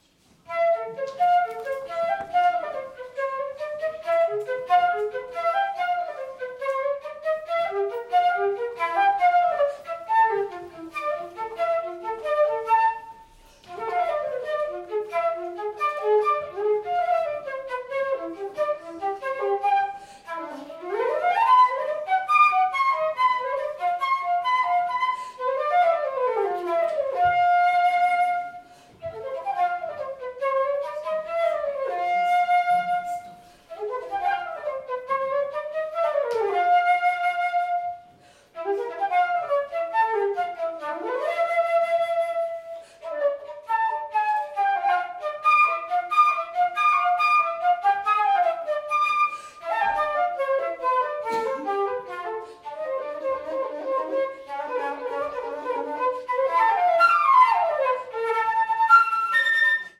Solo
Extrait lors de la visite contée & concert, Musée Vulliod Saint-Germain, Pézenas, juillet 2023